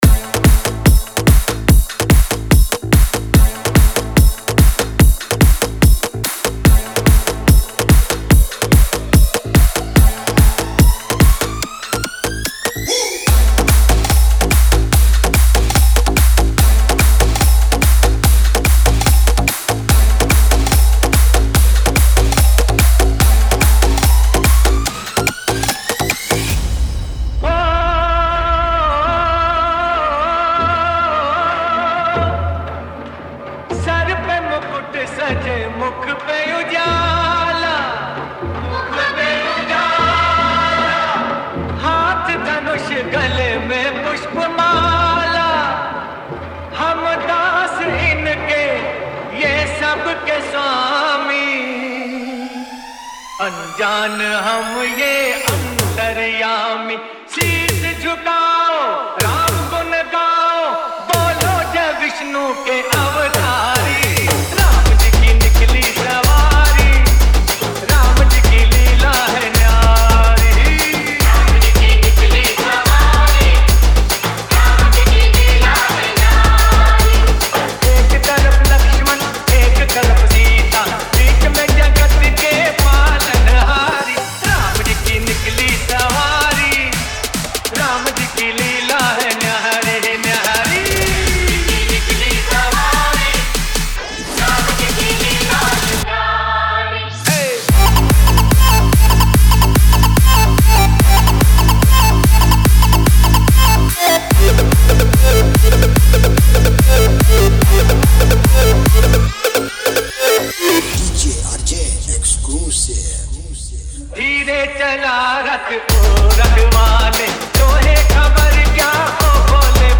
Ram Navami Special Dj